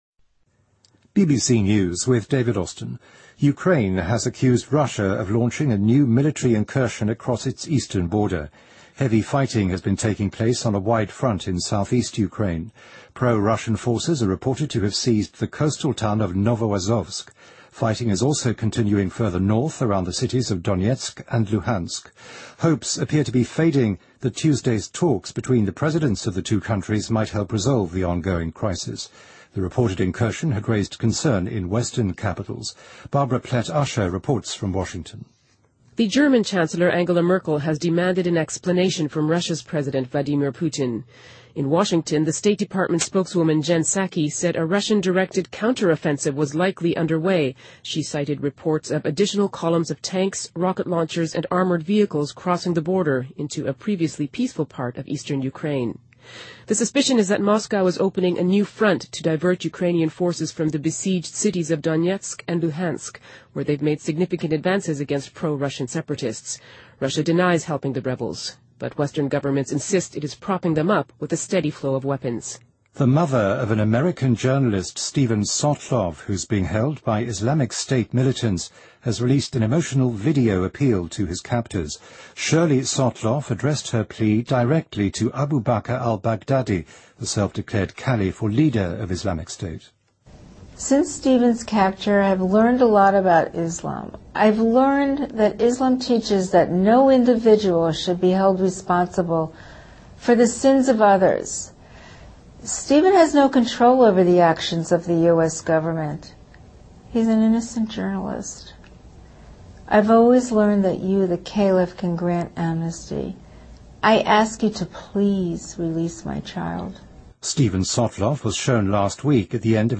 BBC news,乌克兰指责俄罗斯发动新的军事入侵